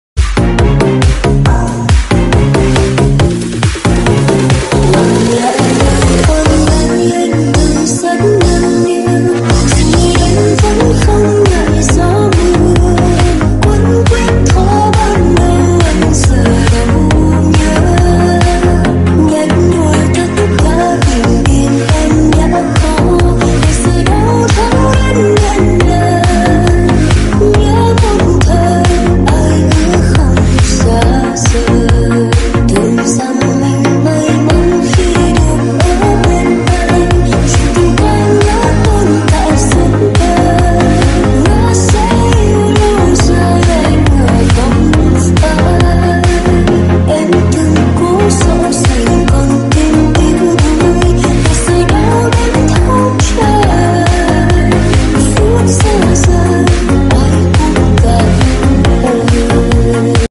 Remix